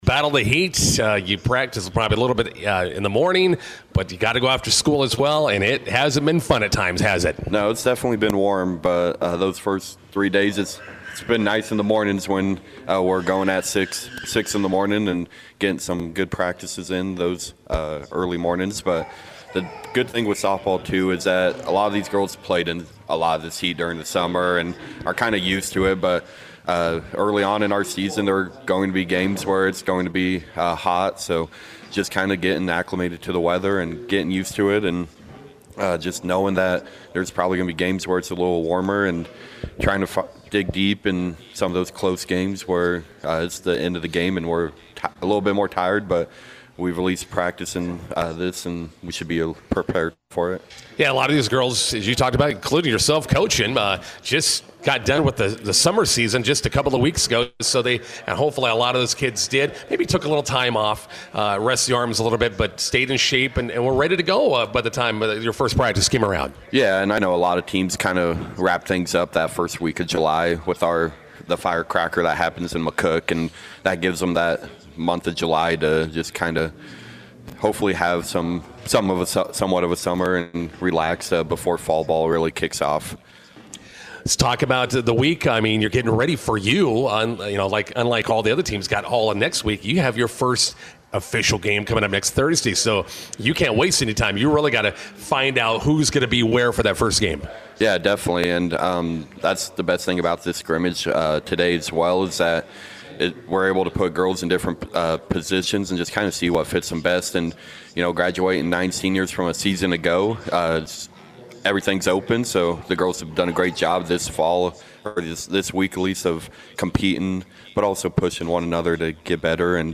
INTERVIEW: Bison softball returns today, first road test at Gothenburg.